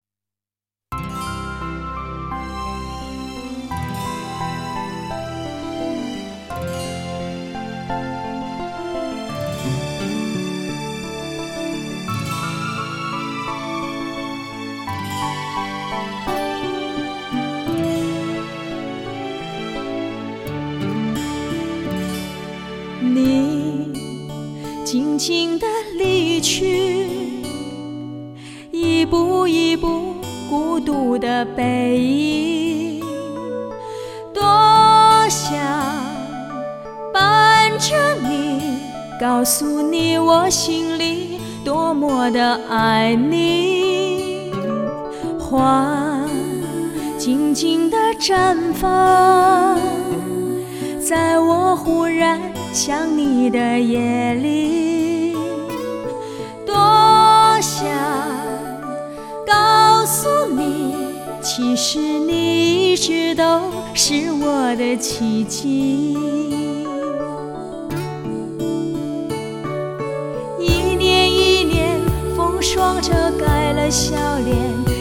高亢的嗓音，投入的表演，